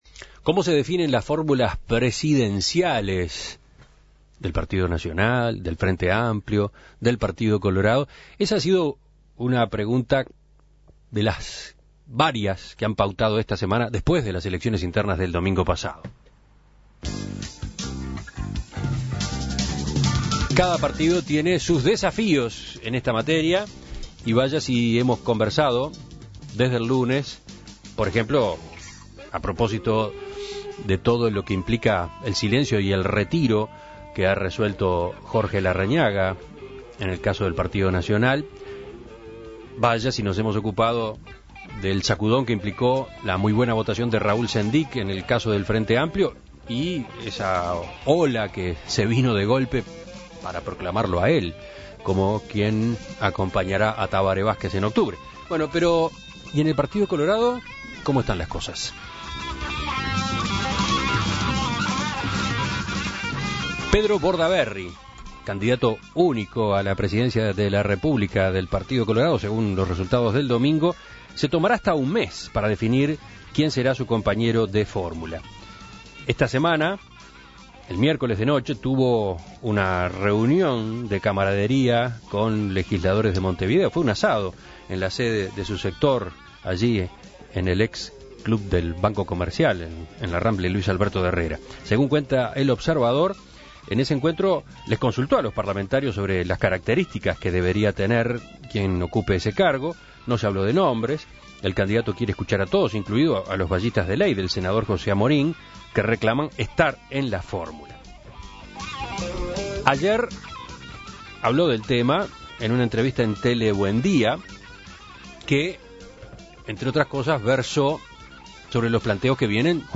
Para conocer la visión del sector de Amorín, En Perspectiva dialogó con Tabaré Viera.